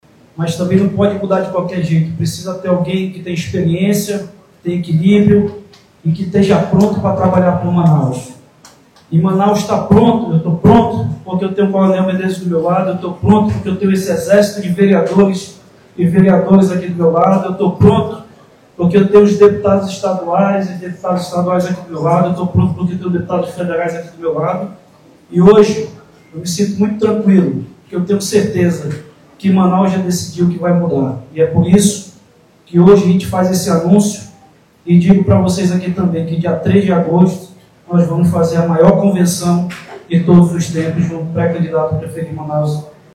O anúncio foi feito no fim da tarde desta terça-feira (30), em coletiva de imprensa com a participação dos dois políticos e outros aliados, no Conjunto Vieiralves, zona centro sul da capital.